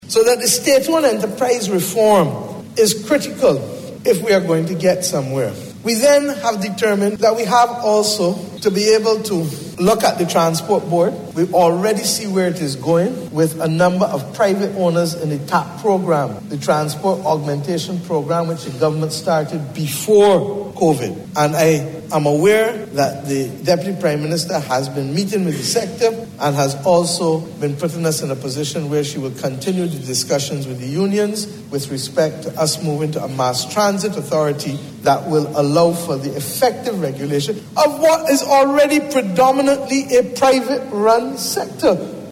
This has been announced by Prime Minister Mia Amor Mottley in the House of Assembly today as she served notice that Barbados is embarking on an accelerated IMF-approved  economic reform program.